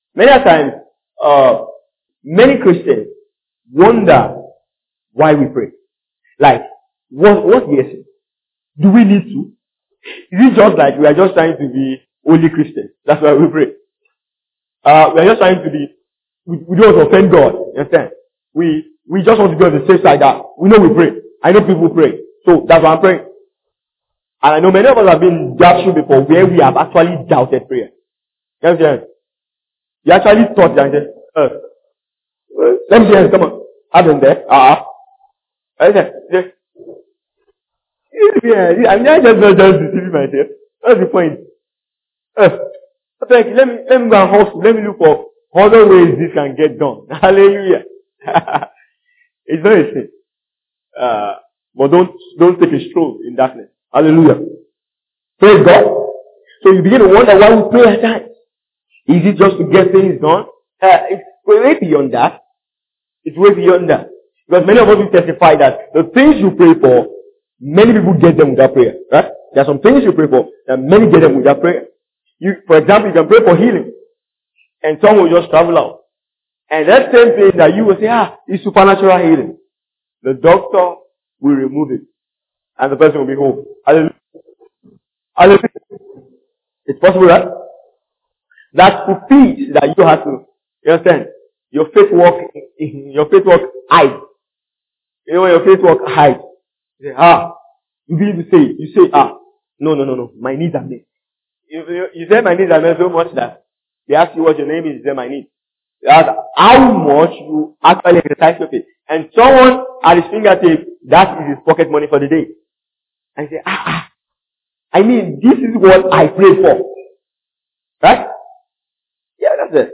Download this audio bible teaching on the subject of prayer for free